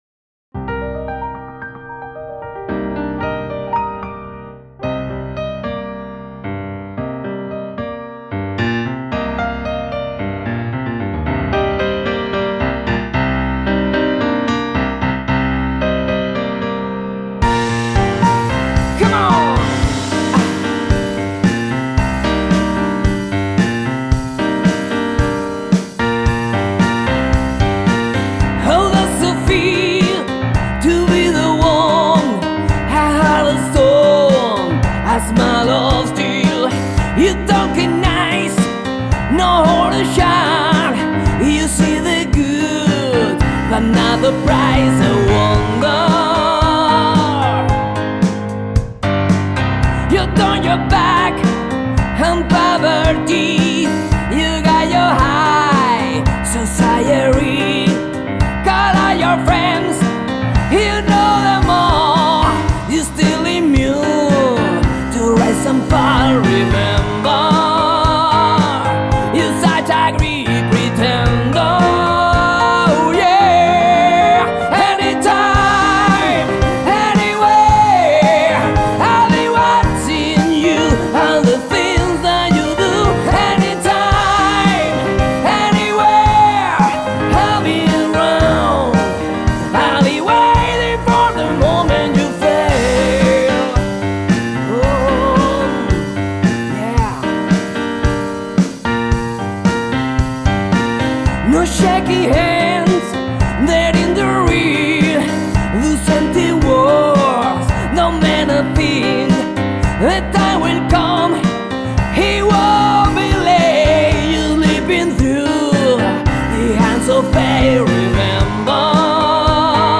Heavy Rock
Grabado en los estudios de Barcelona